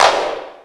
Clap
Original creative-commons licensed sounds for DJ's and music producers, recorded with high quality studio microphones.
Verby Clap One Shot D# Key 10.wav
clap-single-hit-d-sharp-key-19-0IG.wav